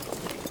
Gear Rustle Redone
tac_gear_20.ogg